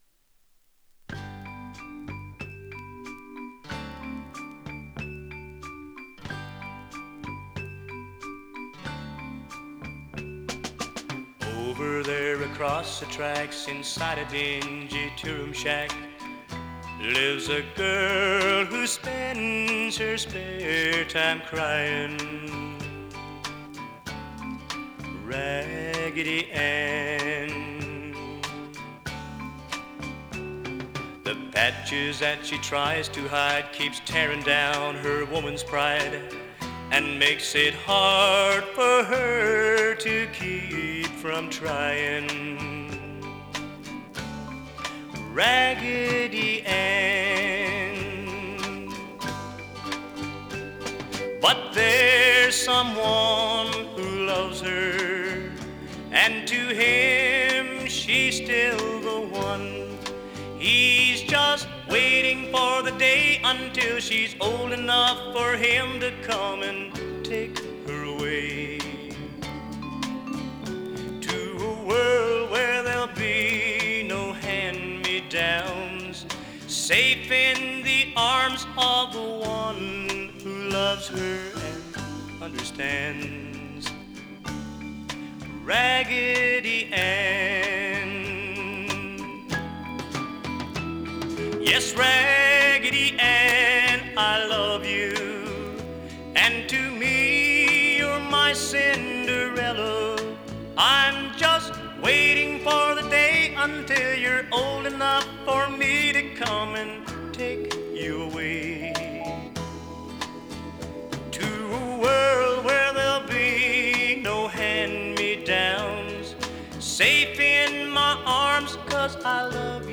vibes, piano and organ